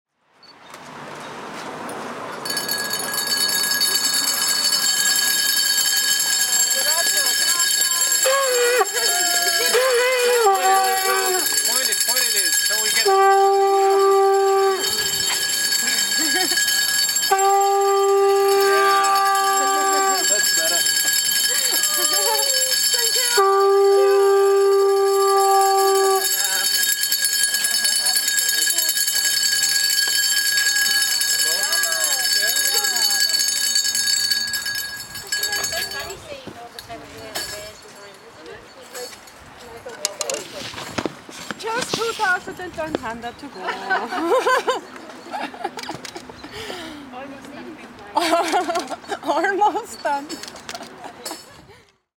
Bells ring and a conch is blown.